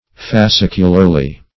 fascicularly - definition of fascicularly - synonyms, pronunciation, spelling from Free Dictionary Search Result for " fascicularly" : The Collaborative International Dictionary of English v.0.48: Fascicularly \Fas*cic"u*lar*ly\, adv.
fascicularly.mp3